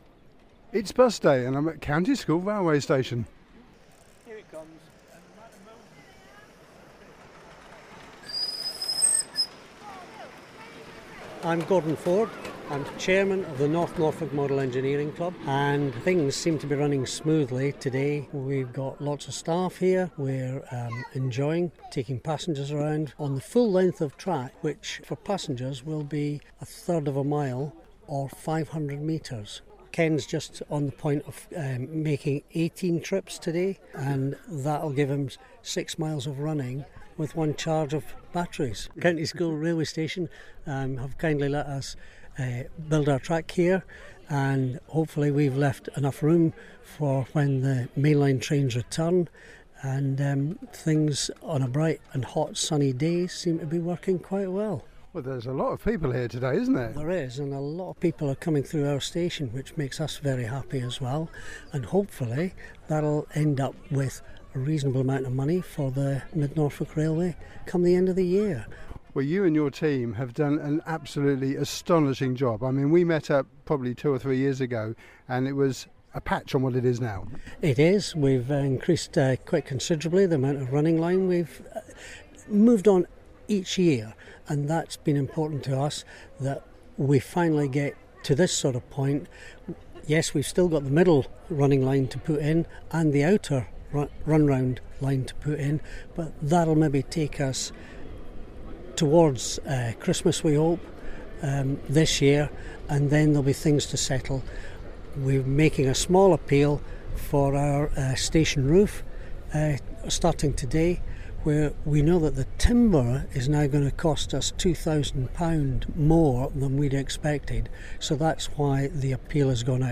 Audio: Wensum Valley Miniature Railway – Bus Day at County School